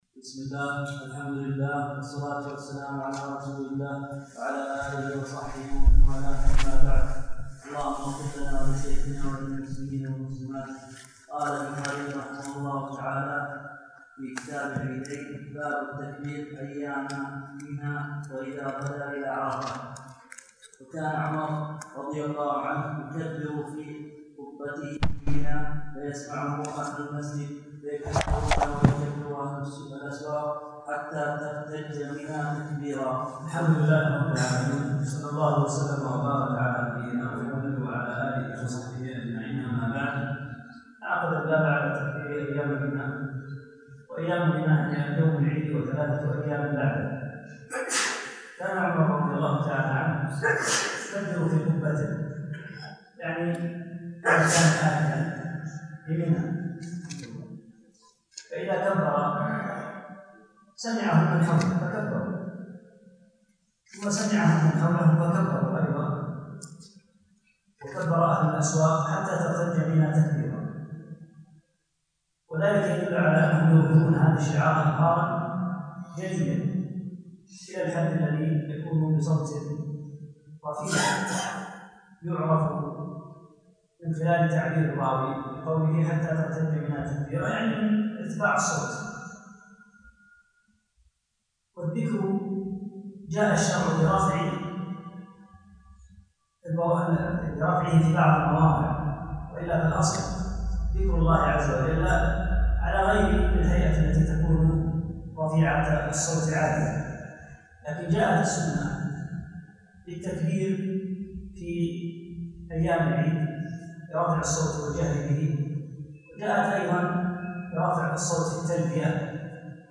3- الدرس الثالث